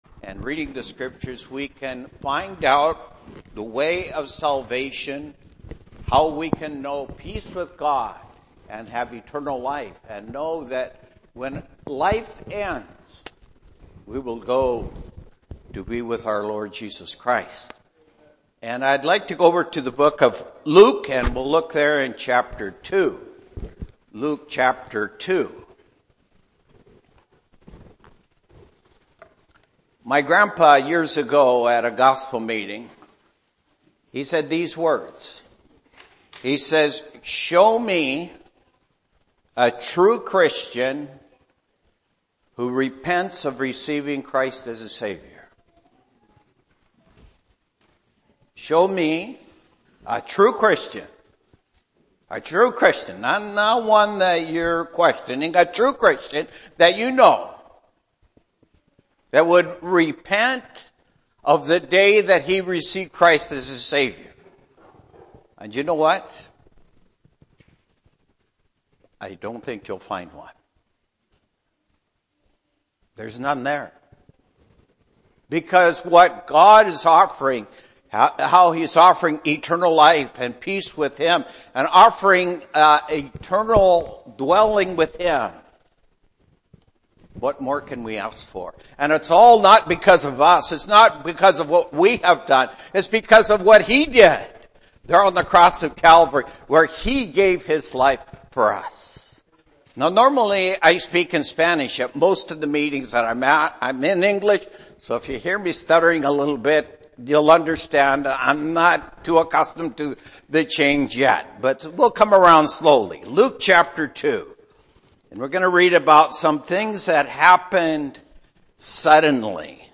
Gospel Meetings 2025